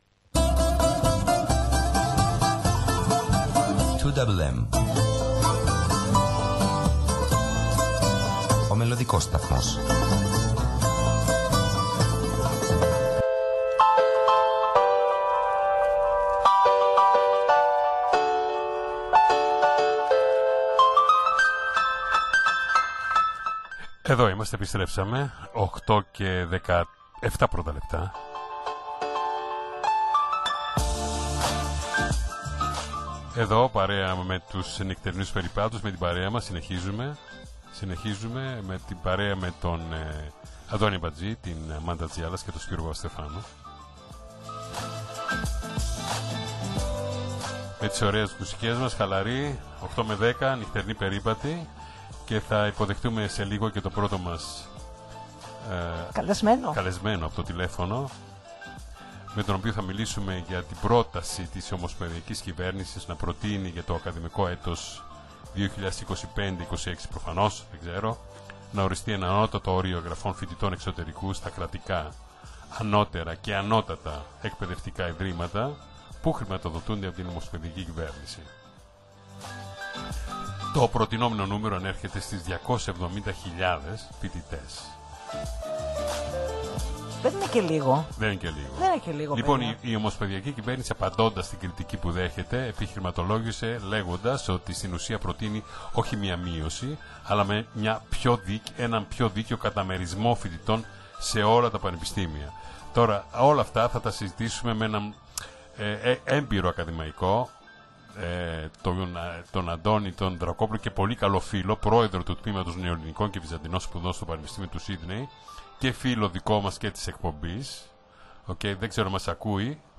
στην διάρκεια ζωντανής του συμμετοχής στην ραδιοφωνική εκπομπή “Νυκτερινοί Περίπατοι